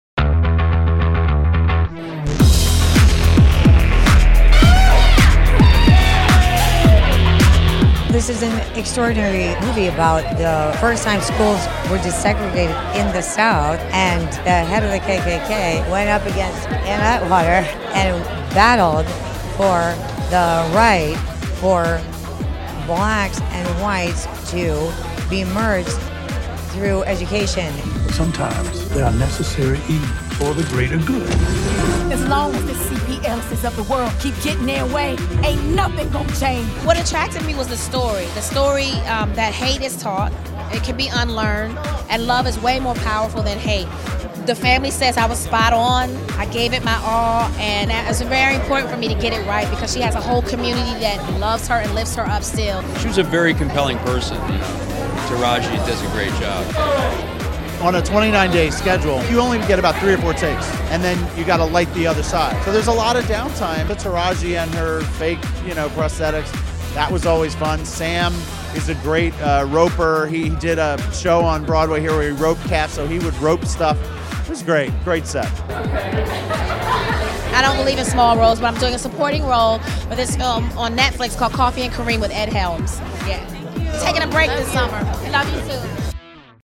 Taraji P. Henson and Sam Rockwell at NYC Premiere of Their Latest Film The Best of Enemies